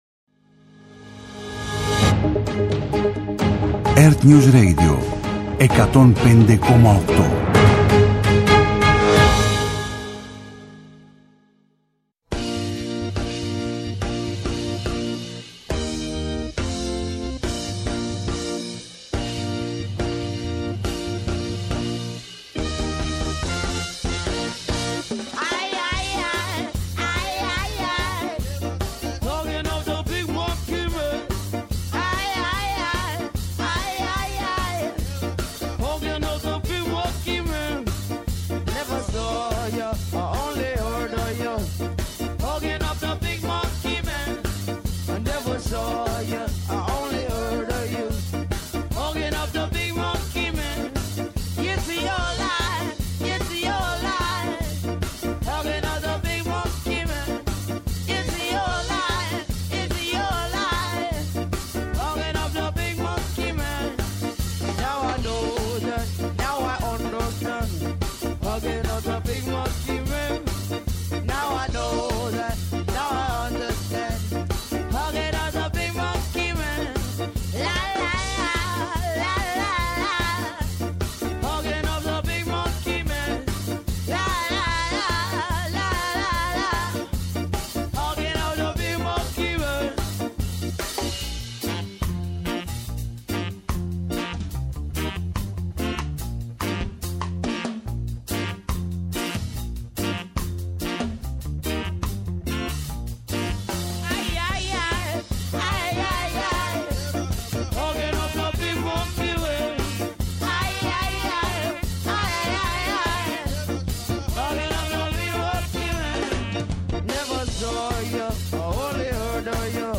Με τη νέα ραδιοφωνική του εκπομπή «Cine-Πώς», που θα μεταδίδεται κάθε Σάββατο στις 21.00, παρουσιάζει την cine-επικαιρότητα και επιχειρεί να λύσει – ή έστω να συζητήσει – τις κινηματογραφικές μας απορίες.